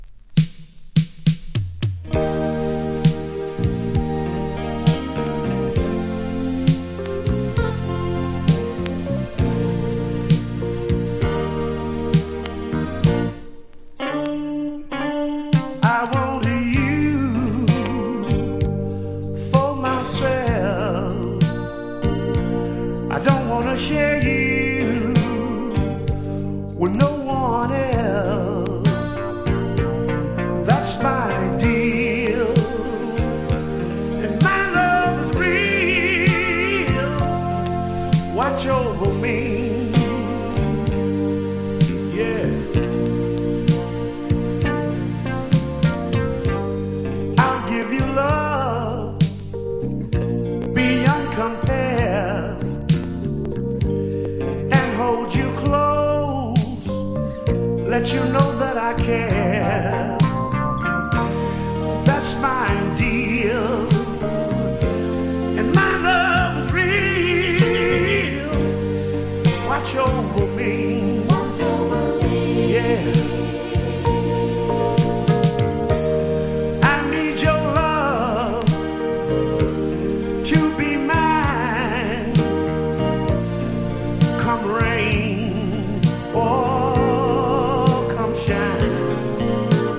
全体を通して甘めのコーラス・ワークとヴォーカルが冴えた名作!!